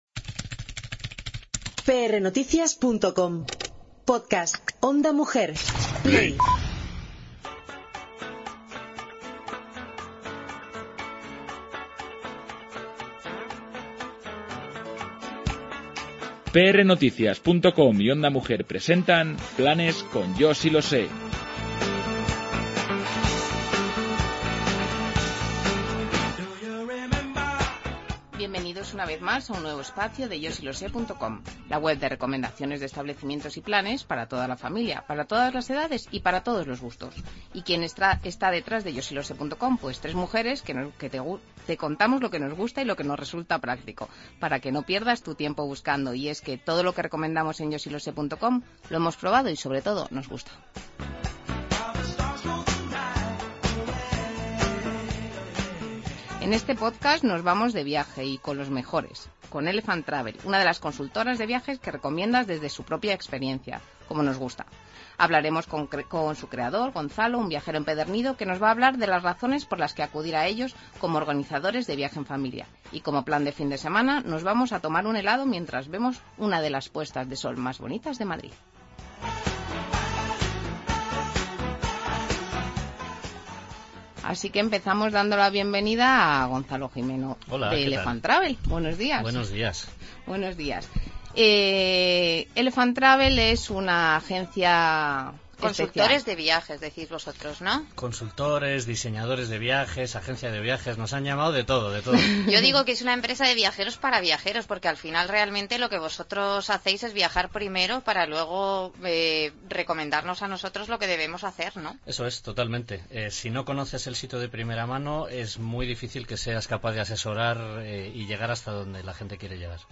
La conversación puede escucharse completa a partir del minuto 31.